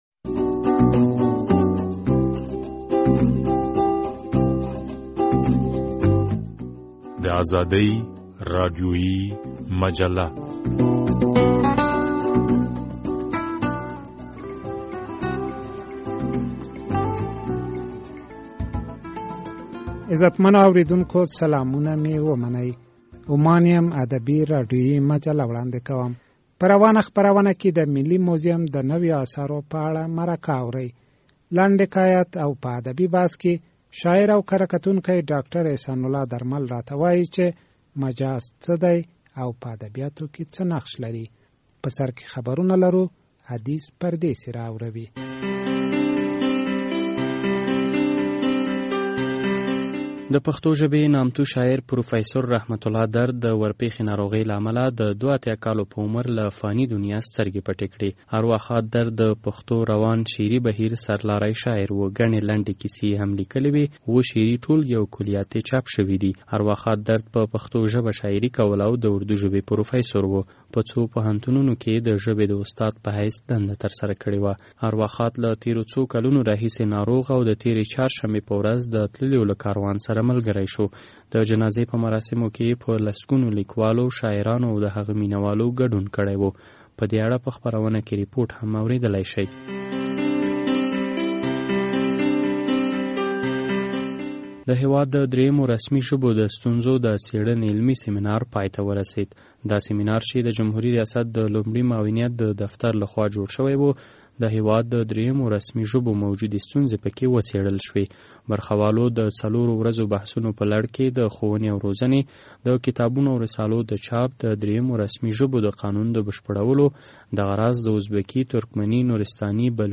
د ازادي راډیو په روانه خپرونه کې د ملي موزيم د نويو اثارو په اړه مرکه اورئ.